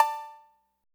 808 COWBELL.wav